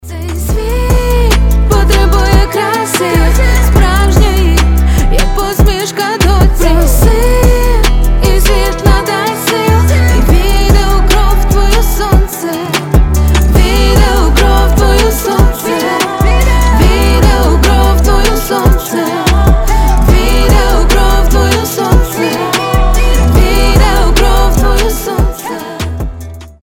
• Качество: 320, Stereo
красивые
женский вокал
мелодичные